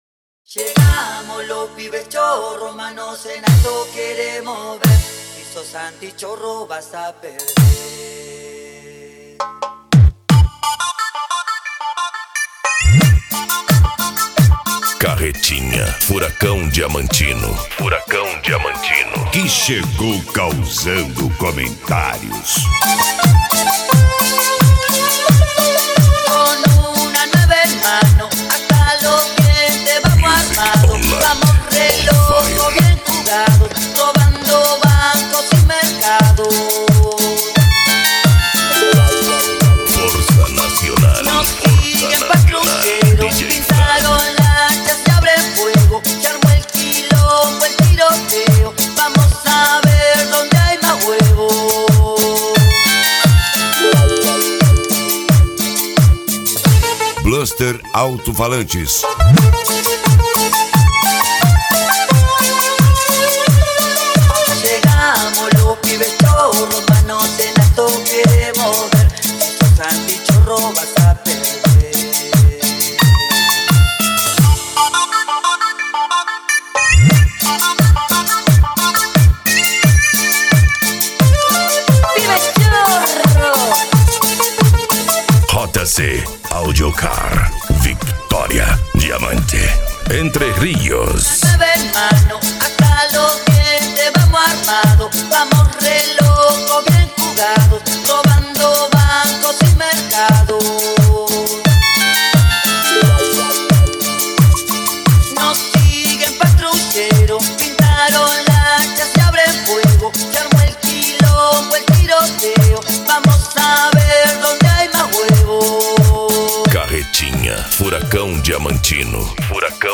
Bass
PANCADÃO
Remix
Trance Music